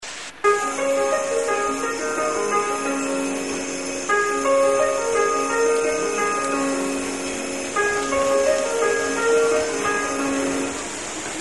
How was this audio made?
One of this trip's objectives was to bring home Yamanote line sounds - most of its stations have a generic buzzer-tone indicating doors closing, but I've wanted a recording of that special, wintry jingle they play at Shibuya, so I brought along my little Sony recorder.